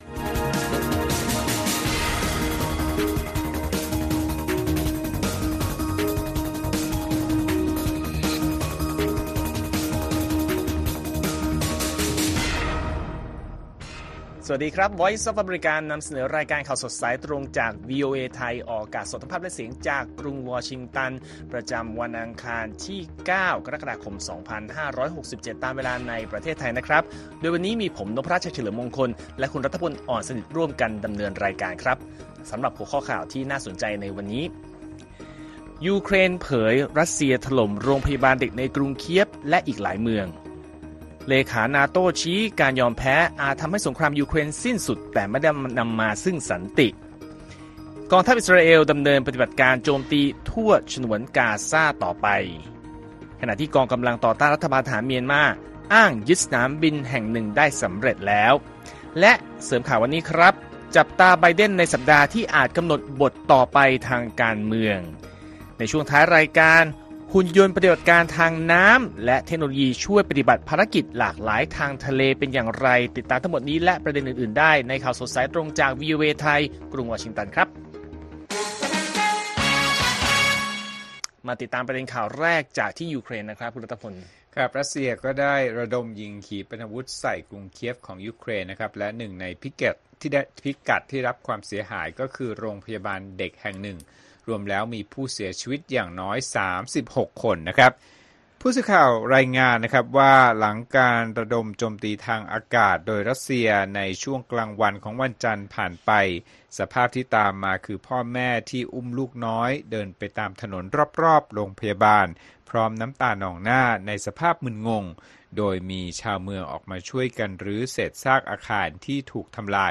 ข่าวสดสายตรงจากวีโอเอไทย อังคาร ที่ 9 ก.ค. 67